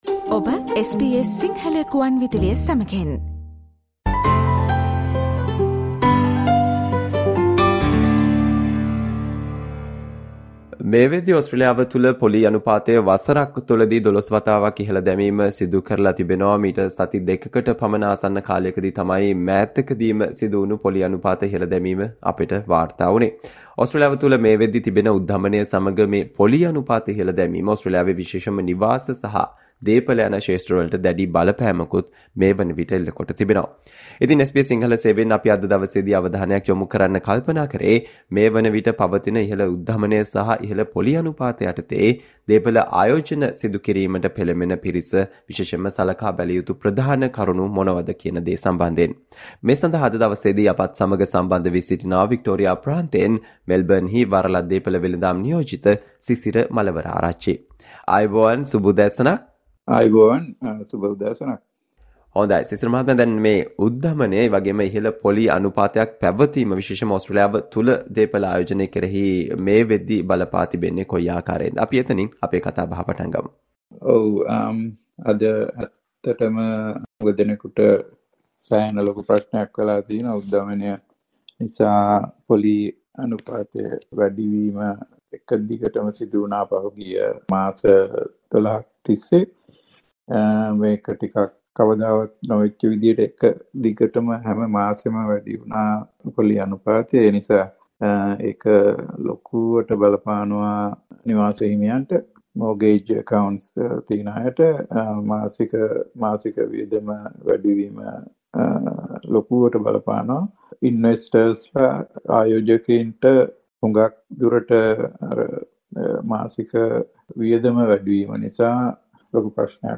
SBS Sinhala Interview